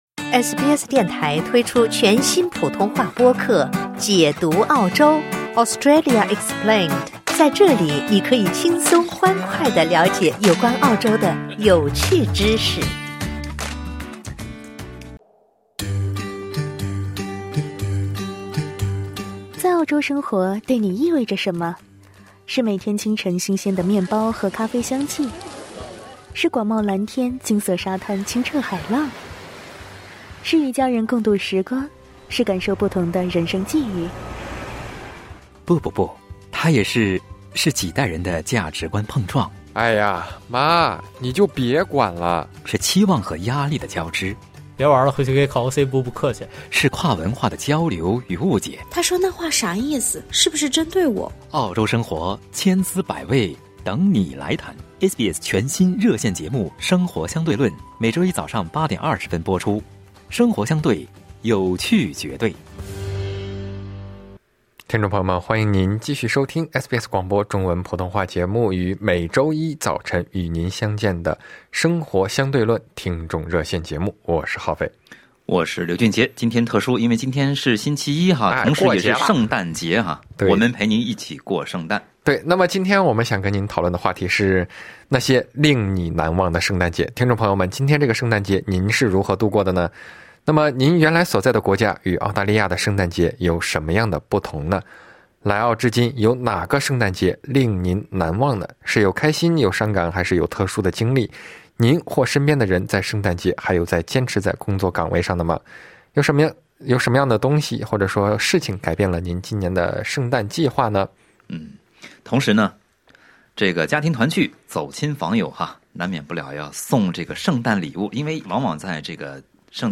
这个圣诞你怎么过 这个圣诞你怎么过 22:35 SBS中文普通话全新热线节目《生活相对论》每周一早上8：20左右播出。